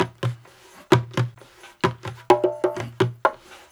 128-BONGO1.wav